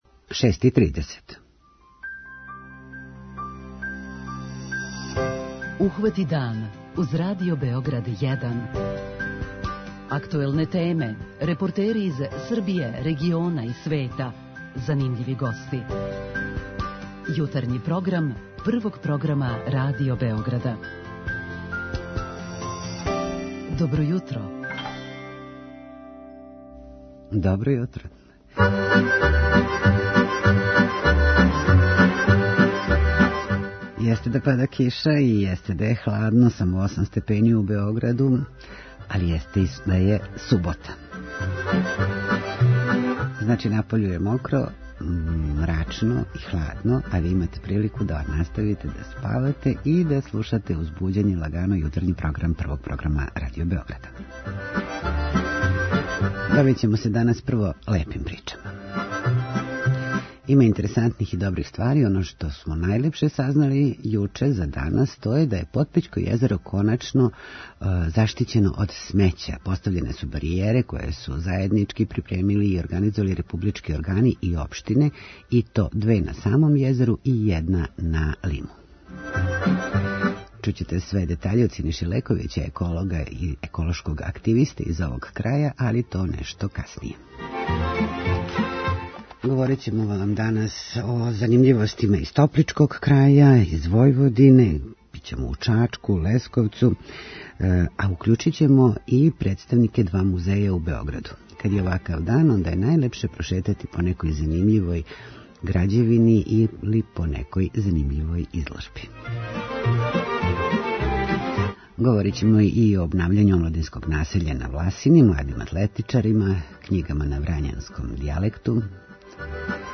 Позваћемо вас и да посетите два музеја у Београду: Музеј примењених уметности који данас слави 71. рођендан, и Музеј Југославије где се још данас може посетити изложба „Прометеји новог века", уз стручно вођење. Наши дописници јављају како се обавља сетва у топличком крају - трошкови су толико увећани да ће ратари редуковати употребу ђубрива и новог семена, и каква је ситуација са узгојем гусака: некад их је било више милиона у Србији, сад само 100 хиљада.
преузми : 26.99 MB Ухвати дан Autor: Група аутора Јутарњи програм Радио Београда 1!